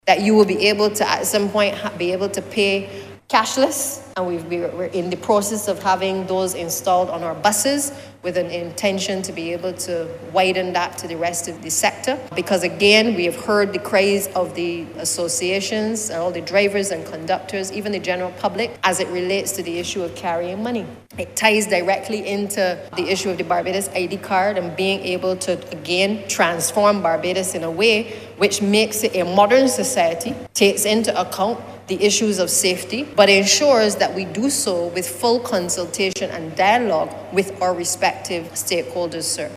This was disclosed by the Minister responsible for Transport, Works and Water Resources, Santia Bradshaw during discussions on the Barbados Economic Recovery and Transformation plan in the House of Assembly yesterday.
Voice of: Minister responsible for Transport, Works and Water Resources, Santia Bradshaw